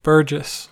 Ääntäminen
Synonyymit burgher middle-class citizen middle class Ääntäminen US Tuntematon aksentti: IPA : /ˈbɜːdʒɪs/ Haettu sana löytyi näillä lähdekielillä: englanti Käännöksiä ei löytynyt valitulle kohdekielelle.